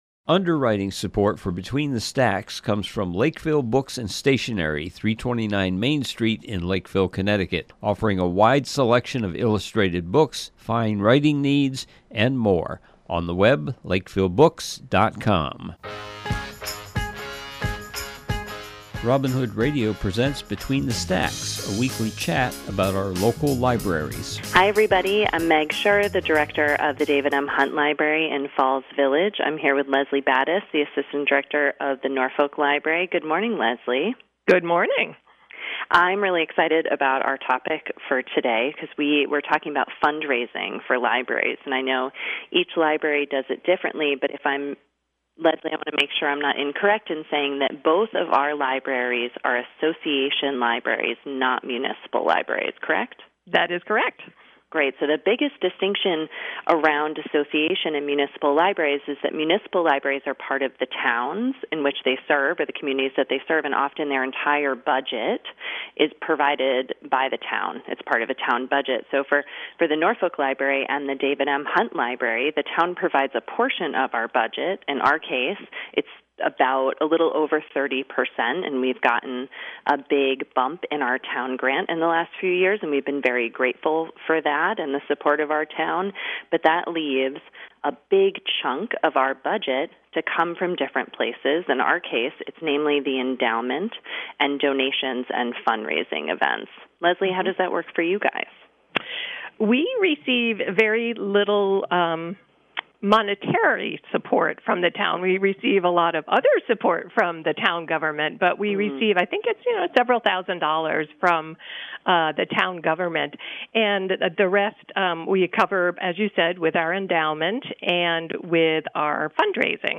This week’s program is a conversation